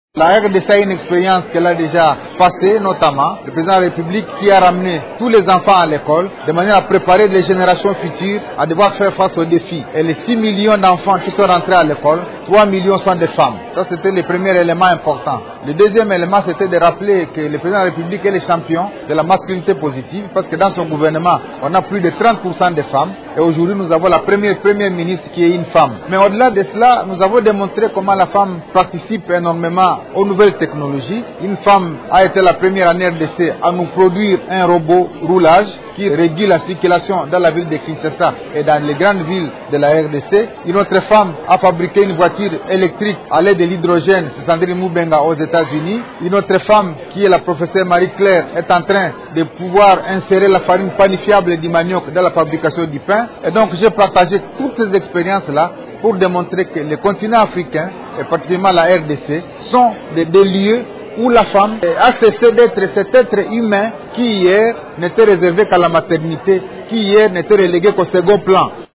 Le ministre du Commerce extérieur de la RDC, Junien Paluku, a été l'invité spécial ce vendredi 25 avril du Forum sur la diplomatie technologique.
Devant des centaines de femmes venues du monde entier, Julien Paluku Kahongya a partagé l'expérience des femmes congolaises qui se sont distinguées dans le domaine de la technologie et de l’entrepreneuriat :